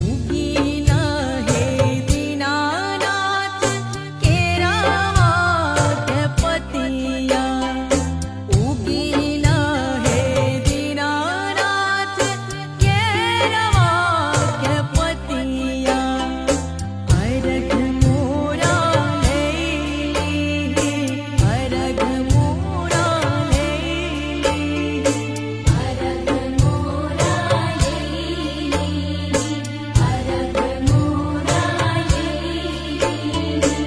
Bhojpuri Ringtones Devotional Ringtones